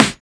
SNARE89.wav